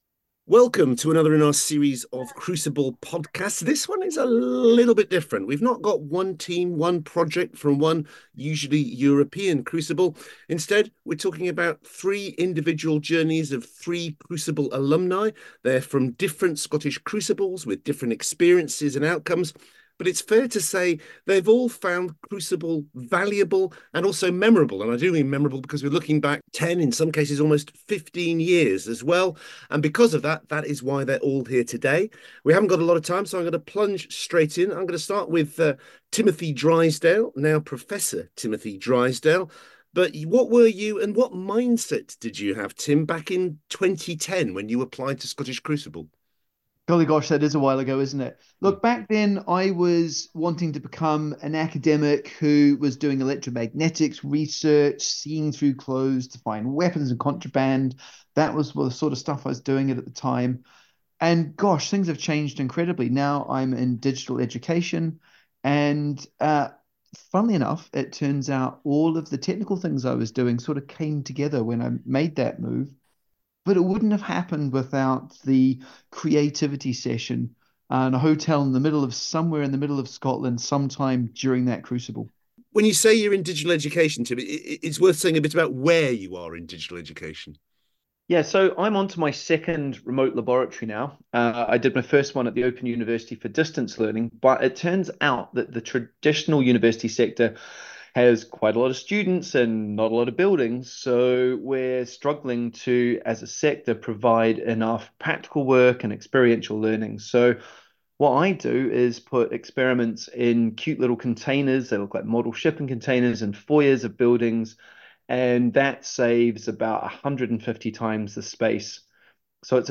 In this podcast, we hear the Crucible reflections of 3 Scottish Crucible Alumni in conversation with Quentin Cooper and learn more about how their research and careers have progressed in the years since undertaking Scottish Crucible:Scottish Crucible Al. Podcast links by Plink.